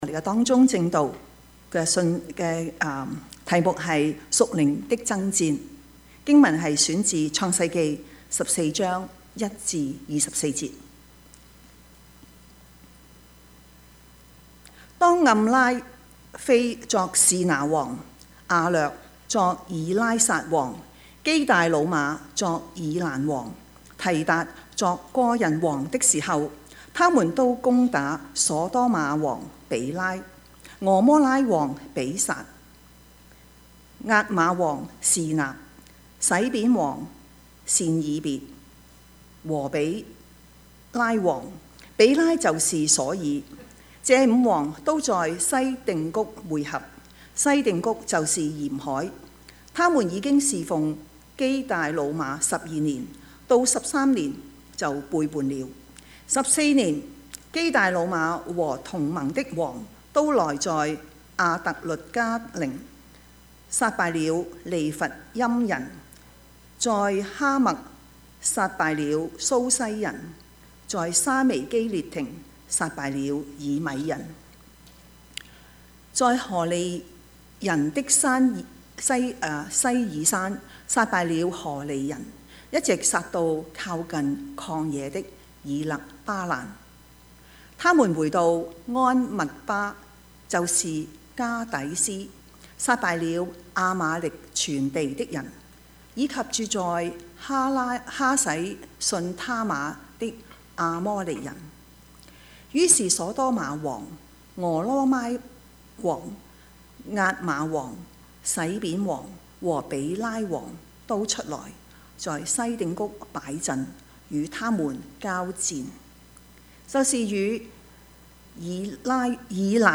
Service Type: 主日崇拜
Topics: 主日證道 « 馮玉祥(二) 何來平安 »